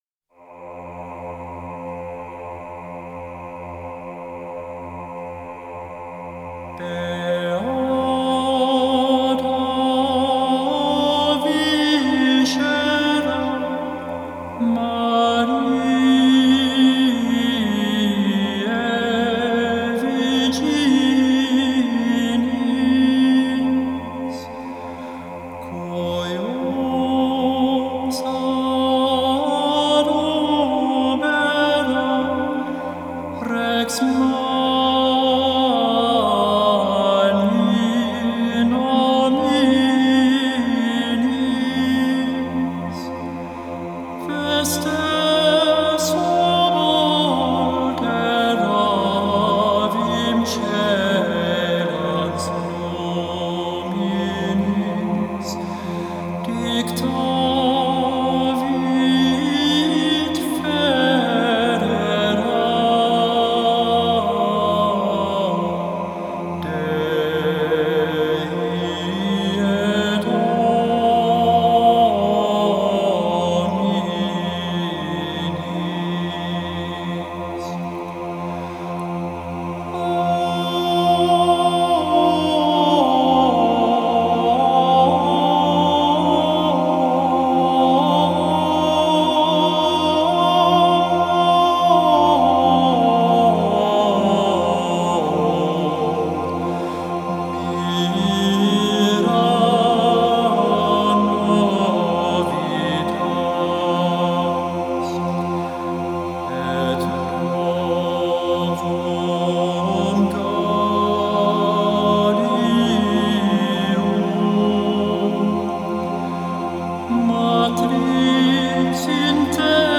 14.-Gregorian-Beata-Viscera.mp3